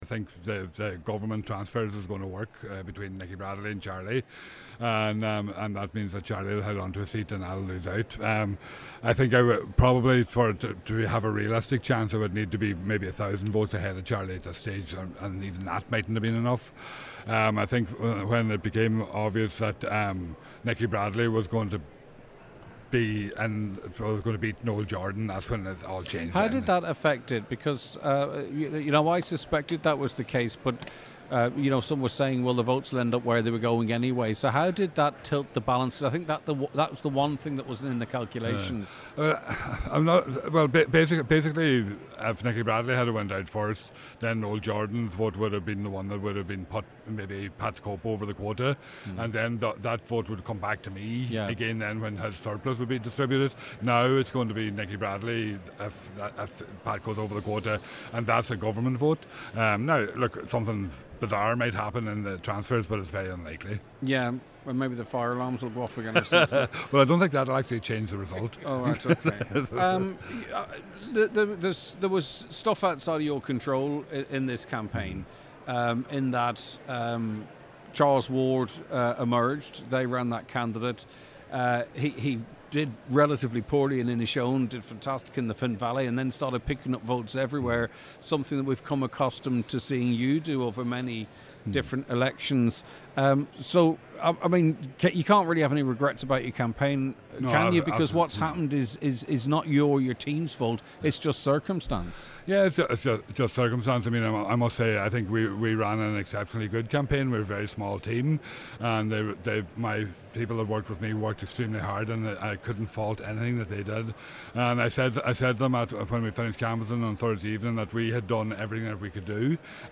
He says unfortunately he will no longer be that voice for Donegal in Dublin: